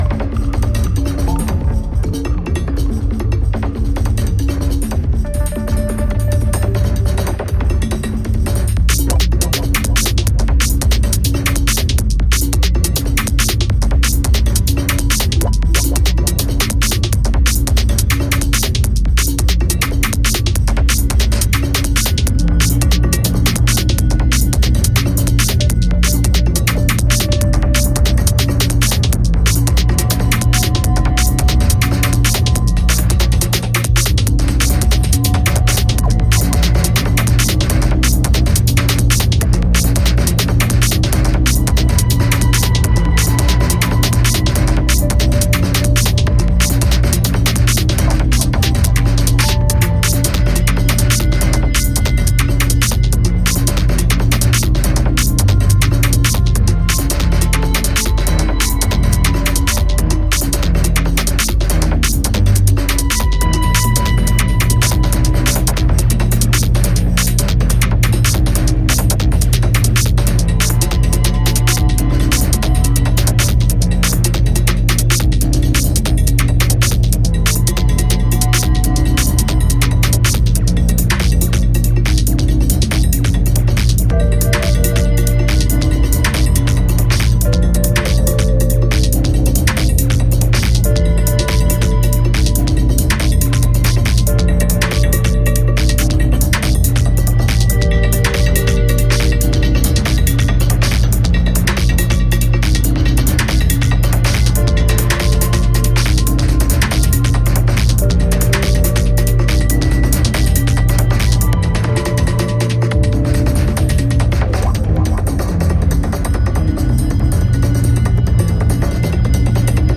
a moody melange of slow, broken dub beats and fizzing synths
Electronix Techno